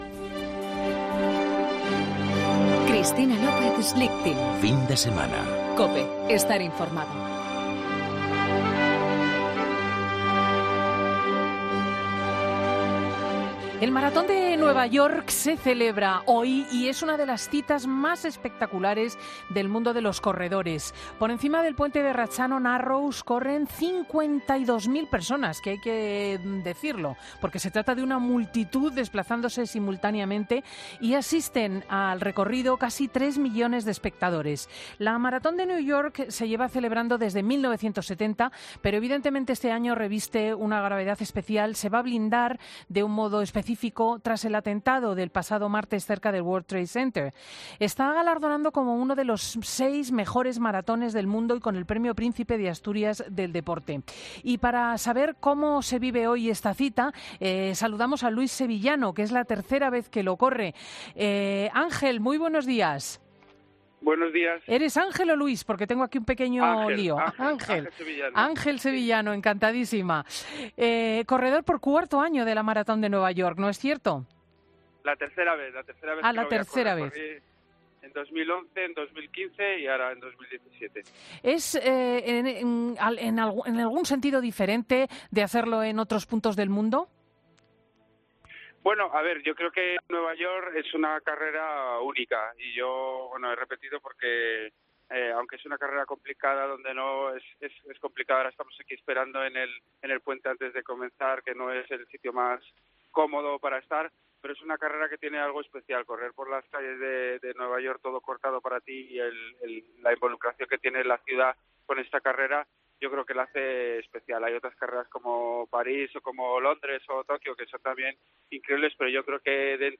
corredor maratón de NY